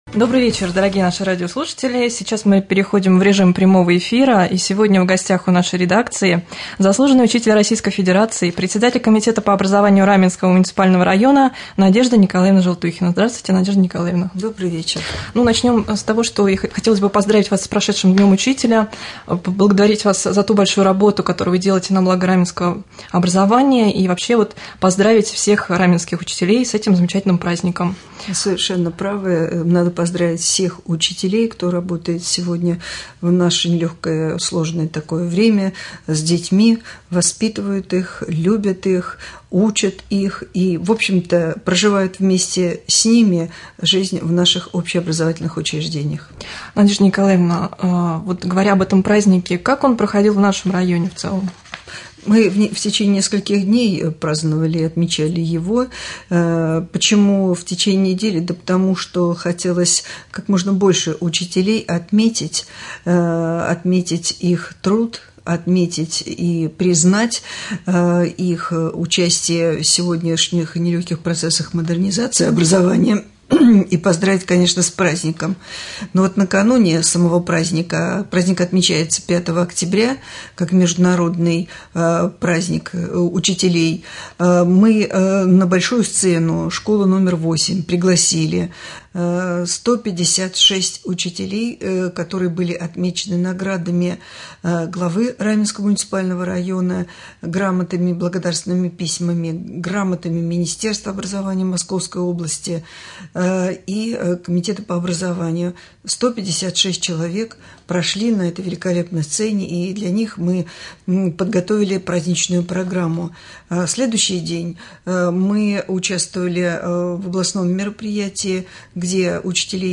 В прямом эфире председатель комитета по образованию Надежда Николаевна Желтухина.